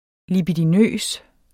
Udtale [ libidiˈnøˀs ]